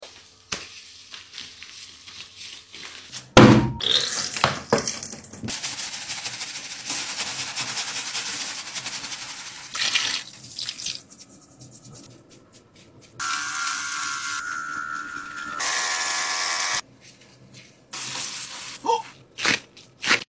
CLEANING SQUISHY ASMR🐤🐤Chick#asmr